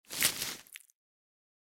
Звук мертвого осьминога на разделочной доске